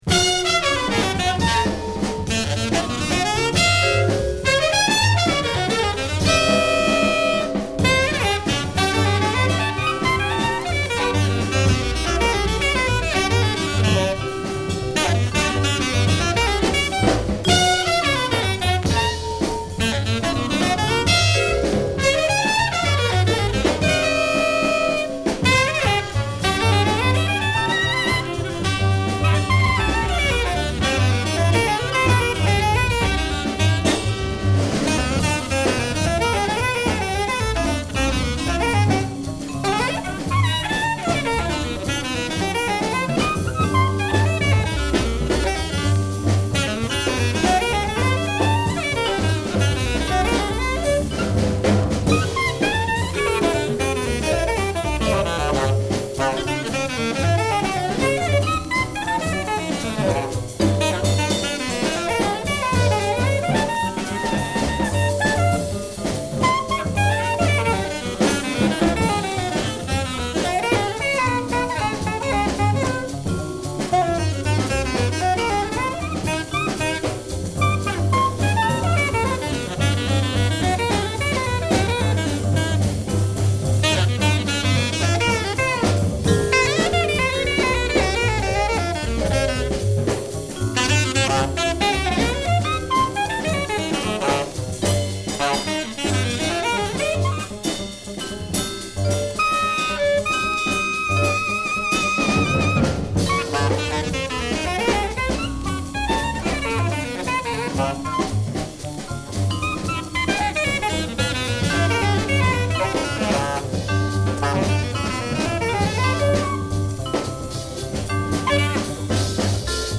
疾走感あるリズムをバックに吹きまくるA1